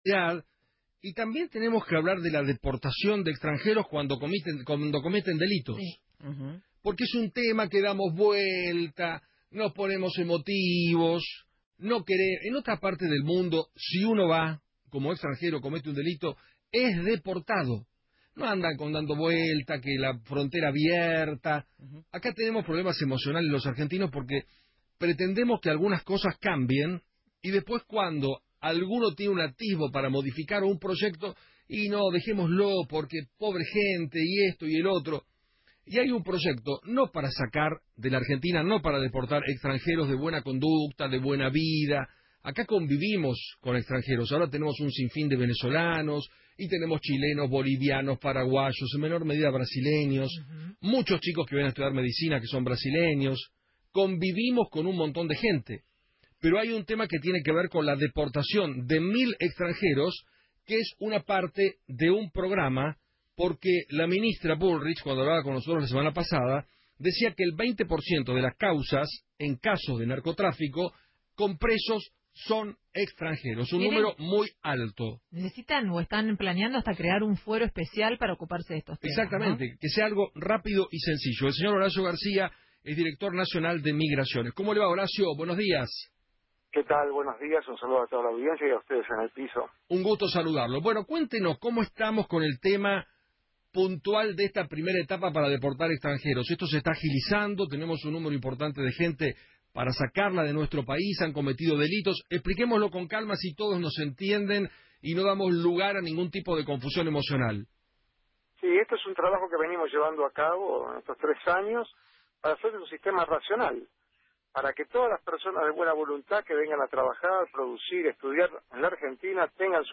Horacio García, Director Nacional de Migraciones, habló en Feinmann 910 y dijo que “Venimos llevando a cabo un trabajo a lo largo de tres años para tener un sistema racional, para que todas las personas de buena voluntad que vengan a la Argentina tengan su lugar, y que los que rompen el contrato de confianza tengan una asignación de responsabilidad y también una penalidad.